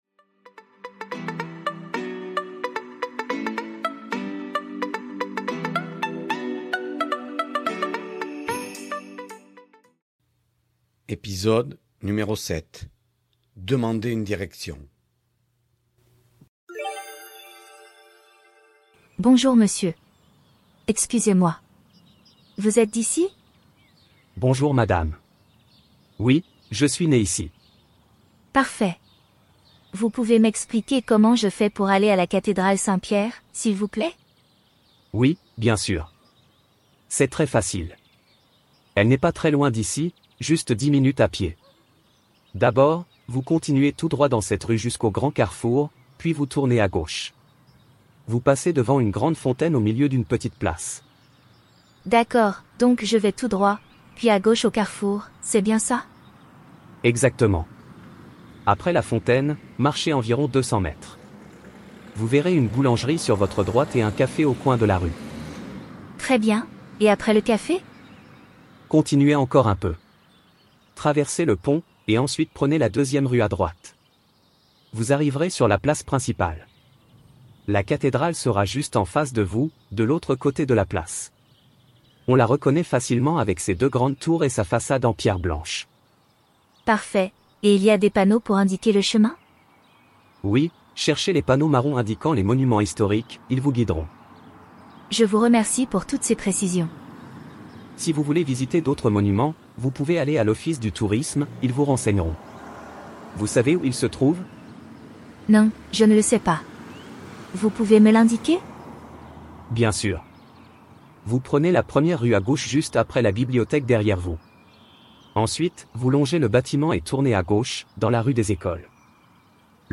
Voici un petit dialogue pour les débutants. Avec cet épisode, vous allez apprendre quelques expressions pour demander une direction.